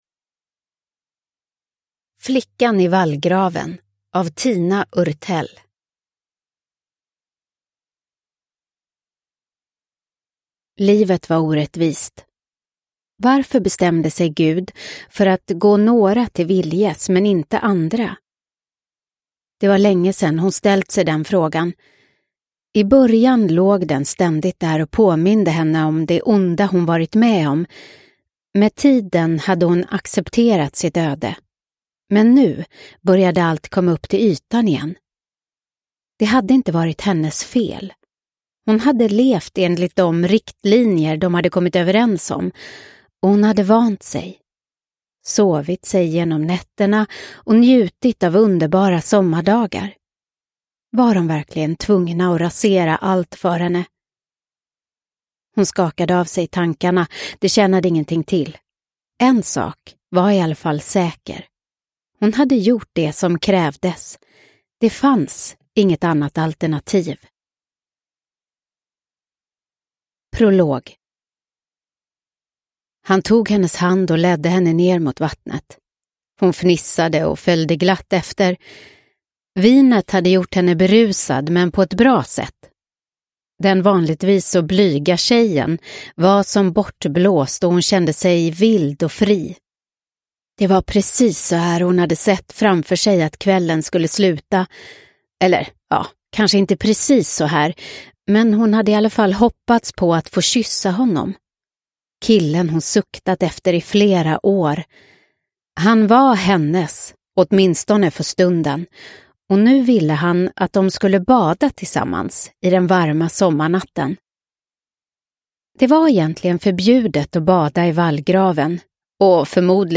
Flickan i vallgraven – Ljudbok – Laddas ner